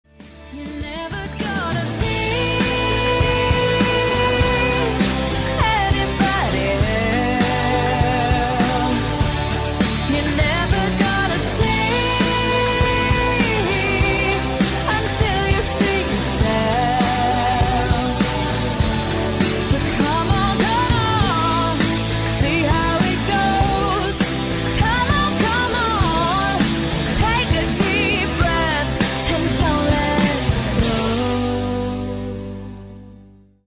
• Качество: 128, Stereo
громкие
мелодичные
Pop Rock
женский рок